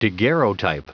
Prononciation audio / Fichier audio de DAGUERREOTYPE en anglais
Prononciation du mot daguerreotype en anglais (fichier audio)